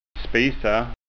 SKÅNSKA UTTAL